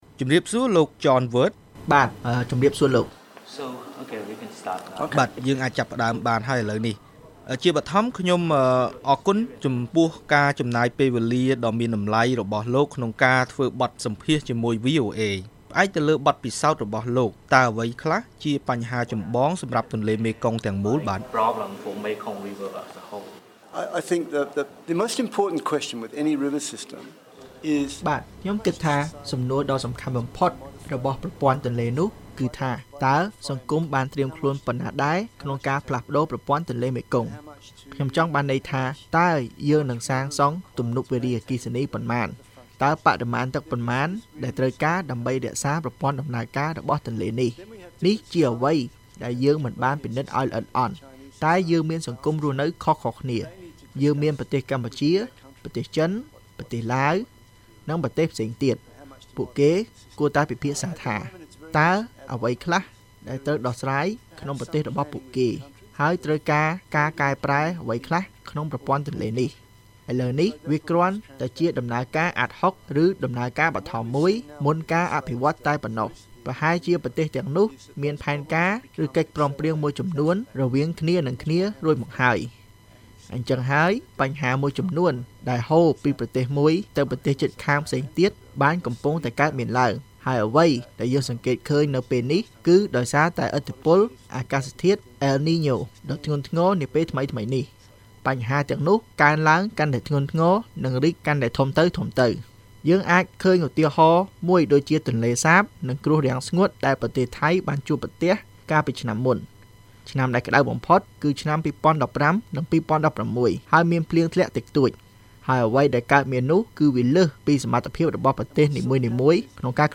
បទសម្ភាសន៍VOA៖ ការគ្រប់គ្រងធនធានទឹកនិងការអភិវឌ្ឍតាមដងទន្លេមេគង្គ